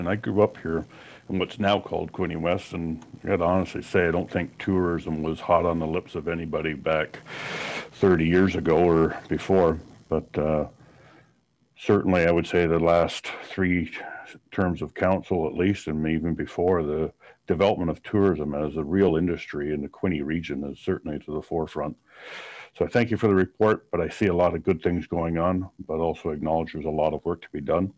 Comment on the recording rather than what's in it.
At Monday’s Quinte West council meeting, council heard an update on the tourism development strategy for the municipality.